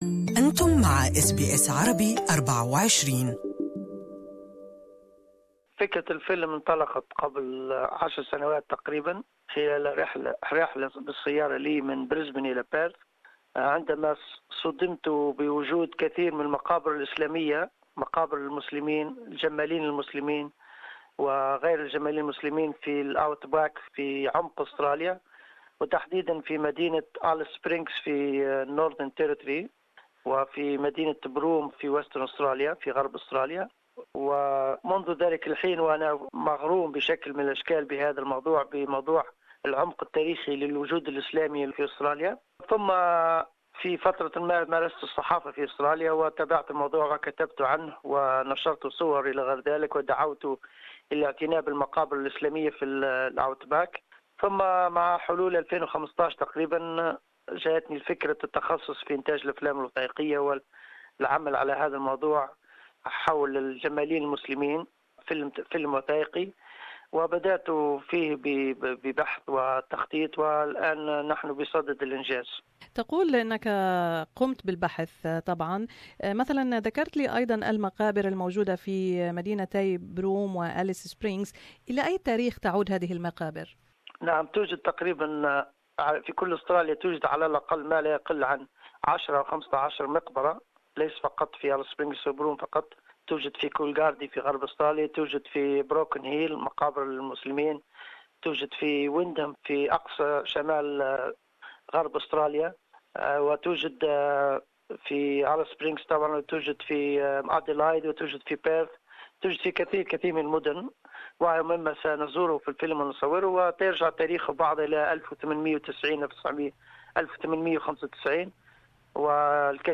He visits outback Australia and documents the journey of what's known as the Afghan Cameleers. In this interview he says, those cameleers who were brought to Australia by the British settlers were not all from Afghanistan but they were known by this name.